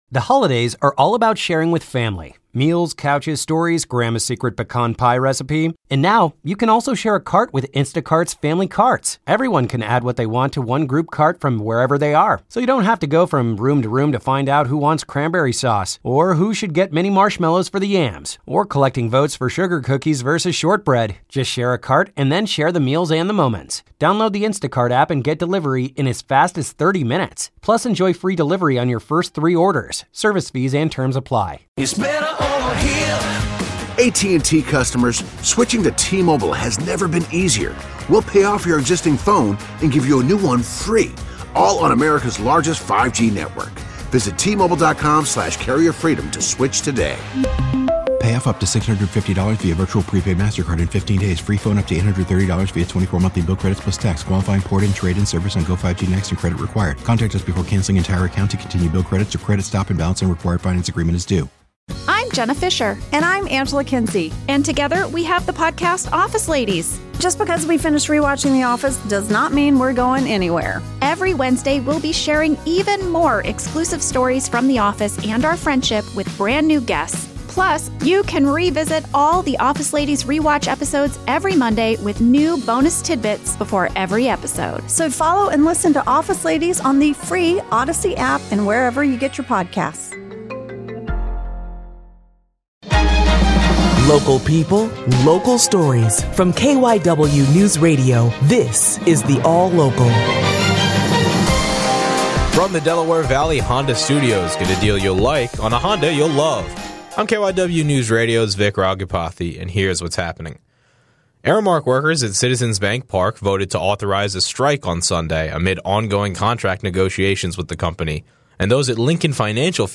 The KYW Newsradio All-Local on Monday, Sept. 2, 2024 (morning edition):